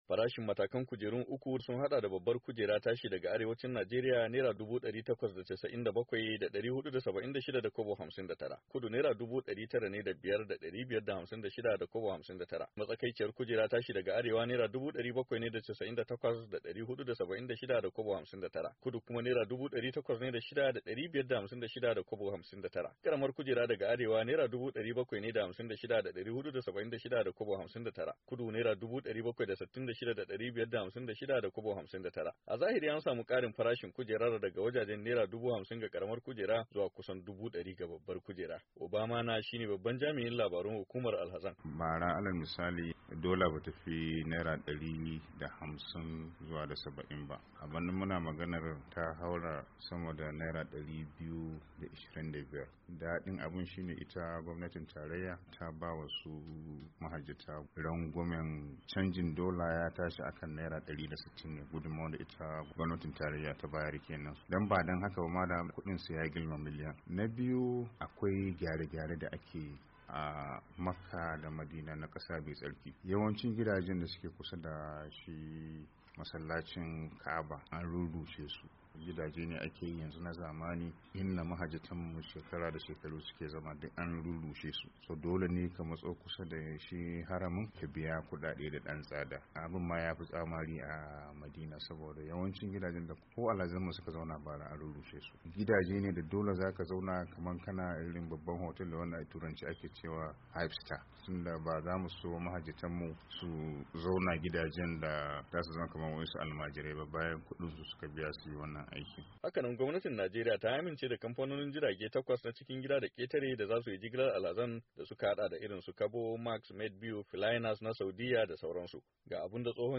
WASHINGTON, DC —